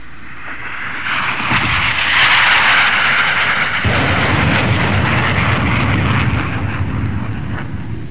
دانلود آهنگ طیاره 67 از افکت صوتی حمل و نقل
دانلود صدای طیاره 67 از ساعد نیوز با لینک مستقیم و کیفیت بالا
جلوه های صوتی